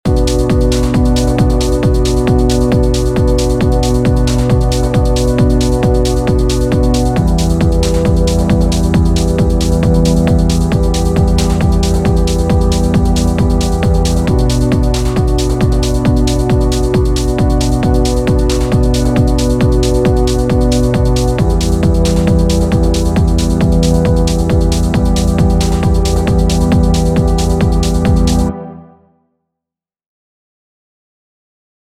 Add a low B to the B minor chords and a low to the E minor chords. Also add a higher G to the fourth bar of the E minor chord.